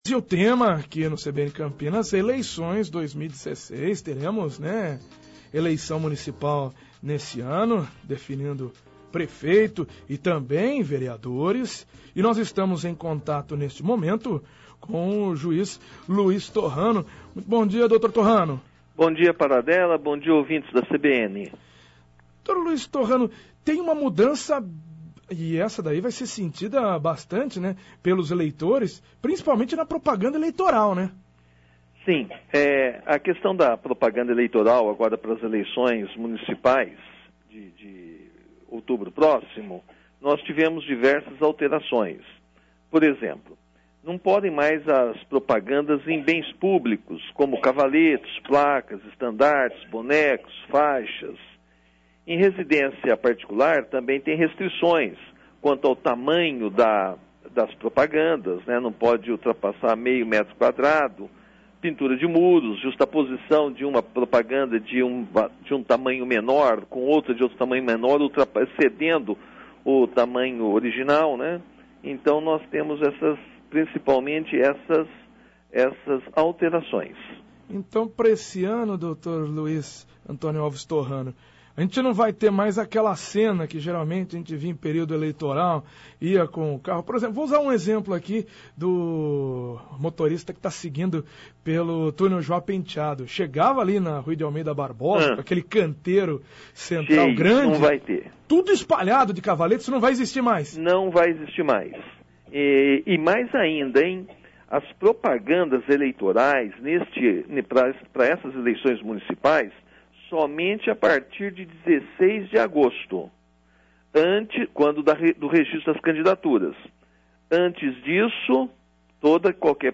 Juiz e Diretor da Cidade Judiciária, Luiz Antonio Alves Torrano fala sobre eleições, período eleitoral, propaganda eleitoral e convocação de mesários - CBN Campinas 99,1 FM